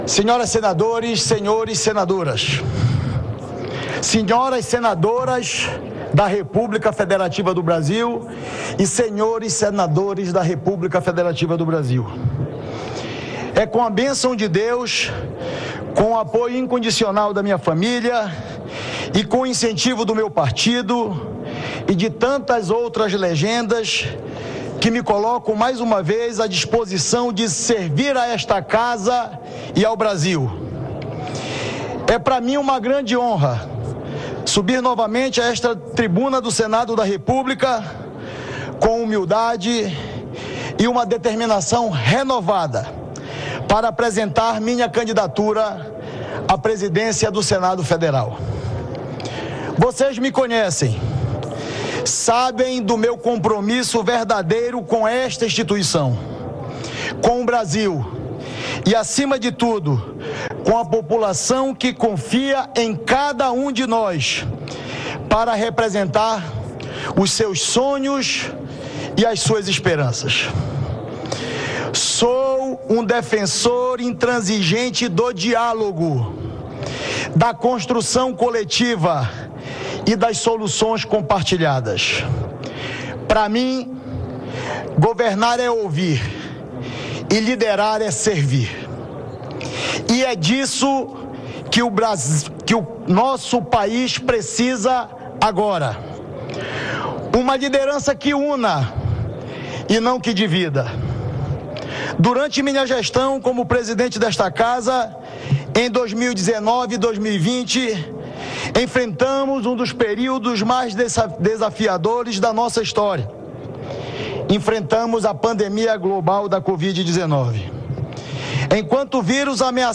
Discurso do candidato Davi Alcolumbre
O senador Davi Alcolumbre (União-AP) apresenta suas propostas para presidir o Senado em discurso na reunião preparatória deste sábado (1º).